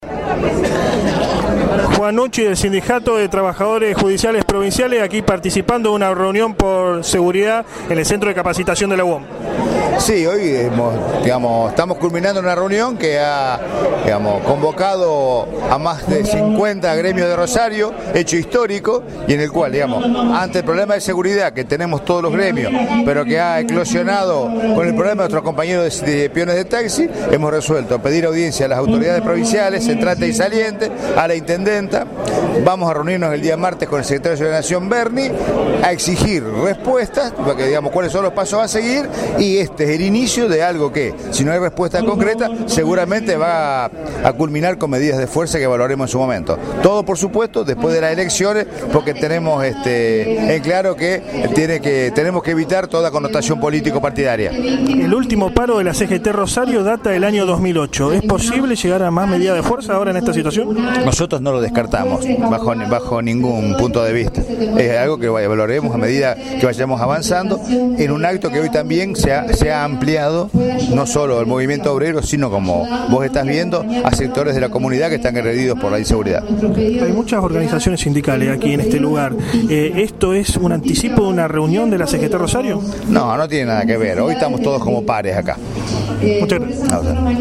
AUDIO ENTREVISTA
Cuna de la Noticia estuvo presente en la reunión abierta que mantuvieron diversos gremios con motivo de la honda inseguridad que vive la ciudad.